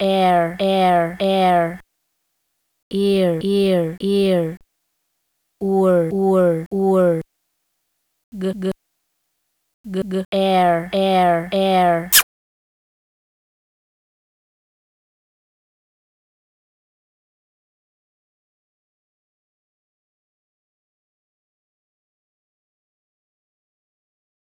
Wunderbar, pure Lyrik, das heißt, vieldeutig und mit dieser gewissen Leichtigkeit hingesagt.
Gelungen auch die überraschende Wendung nach Er-Er und Wir-Wir nach Ur-Ur (oder Uhr-Uhr) und die prosaische Improvisation mit den Stotterphonemen.
Ebensogut kann es ein Zischen, ein Entweichen, ein schwer zu bestimmendes air movement sein.